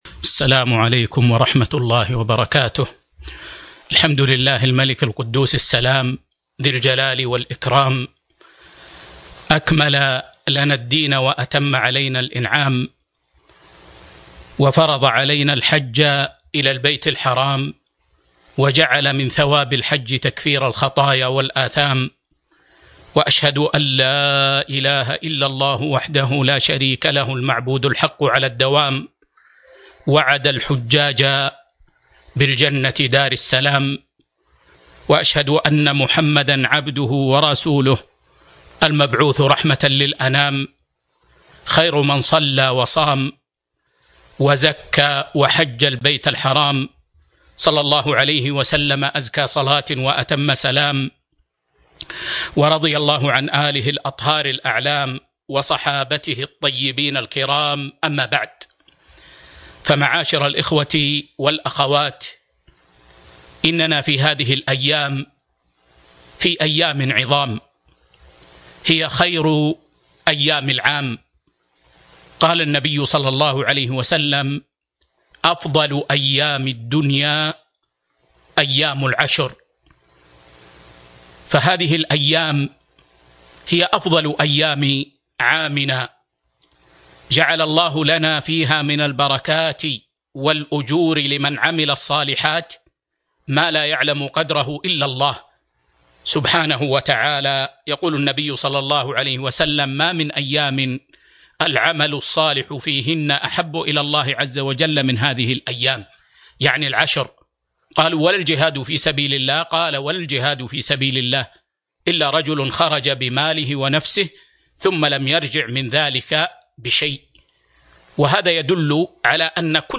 محاضرة قيمة - إقامة الحج في ظل جائحة كورونا بين الأدلة المرعية والمقاصد الشرعية 1 ذو الحجة 1441 هــ